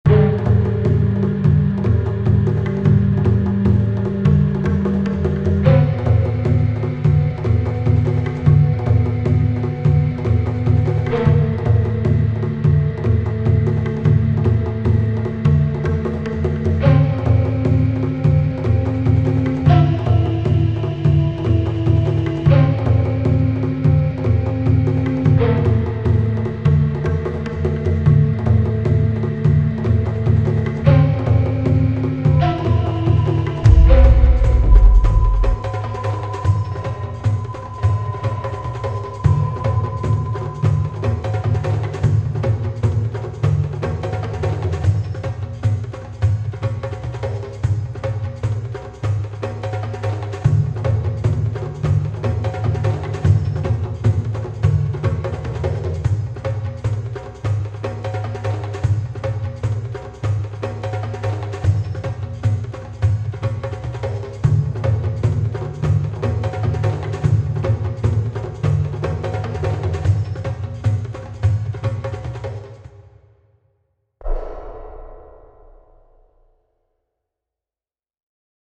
Desert Oasis (Faint Background Music)
So I redid one of the background music for my game. The music is put in the background and is meant to add atmosphere to the game. It is faint so that the player won't notice it much.